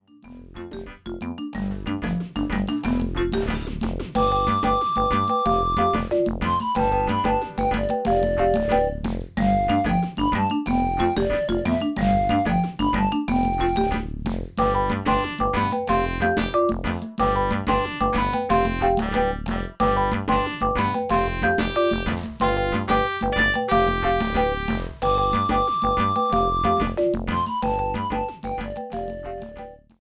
fade in and out
This is a sample from a copyrighted musical recording.